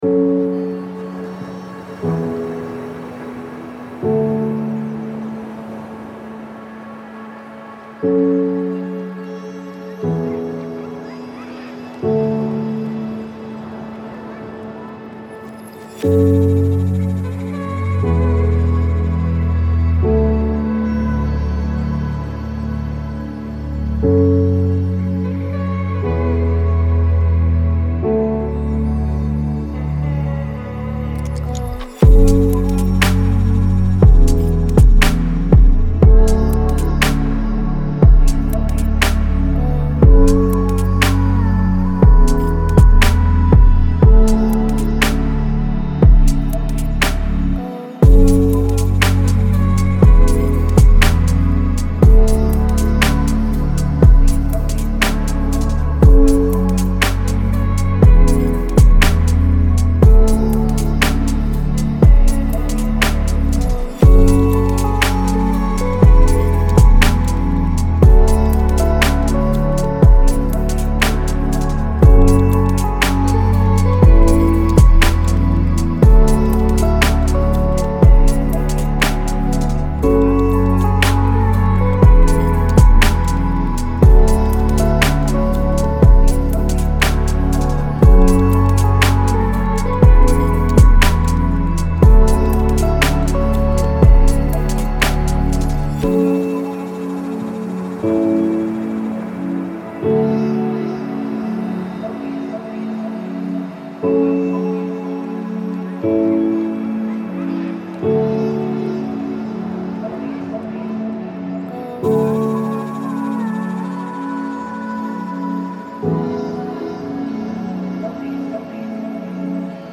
поп/электроника